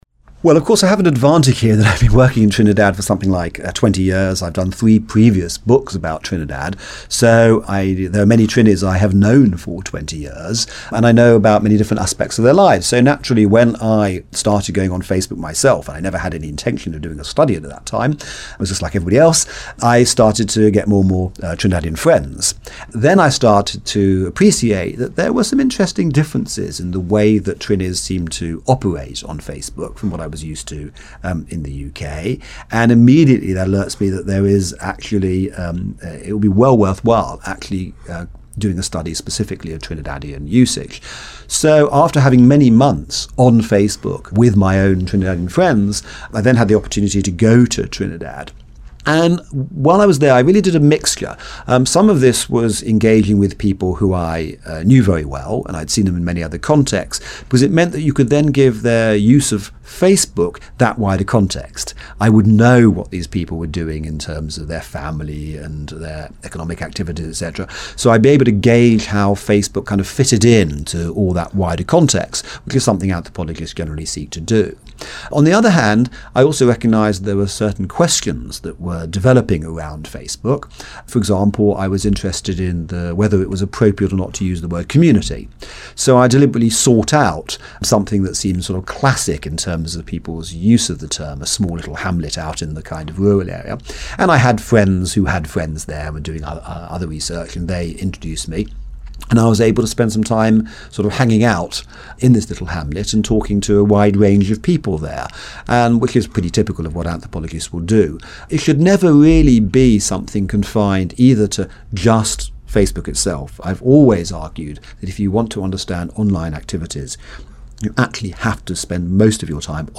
To listen to my complete interview with Daniel Miller click here.